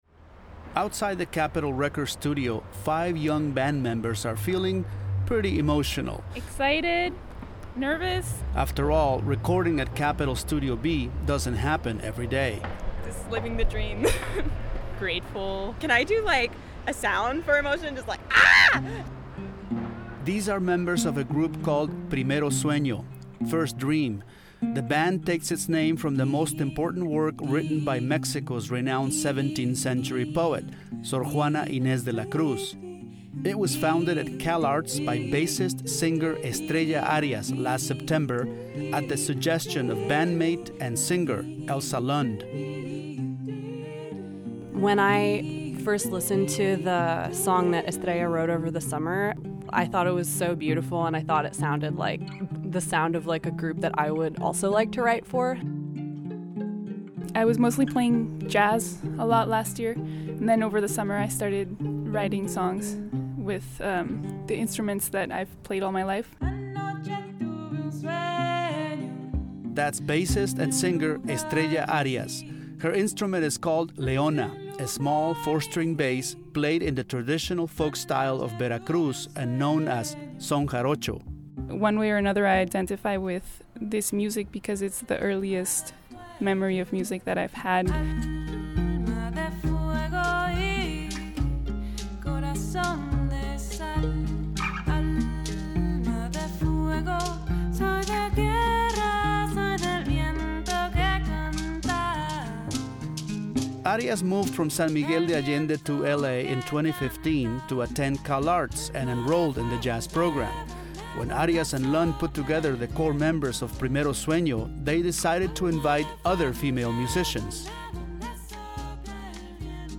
For the last twenty-eight years, music students at CalArts have been recording at the renown Capitol Studio B. Outside the studio, five young band members are feeling pretty emotional.